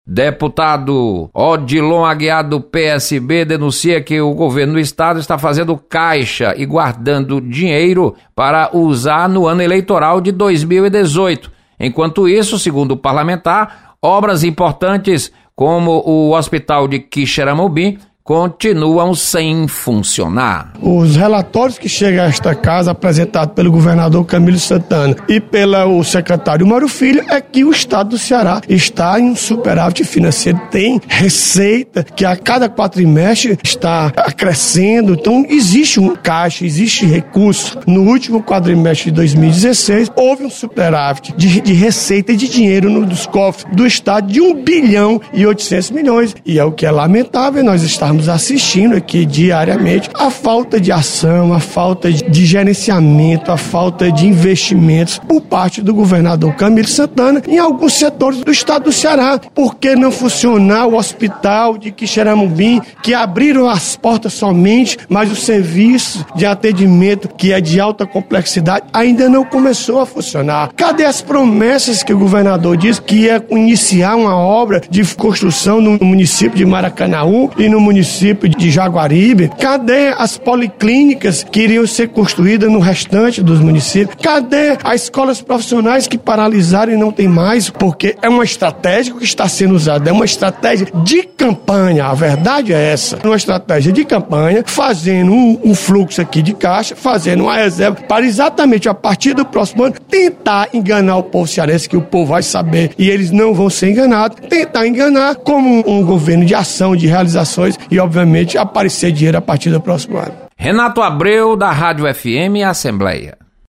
Deputado Odilon Aguiar critica gestão estadual. Repórter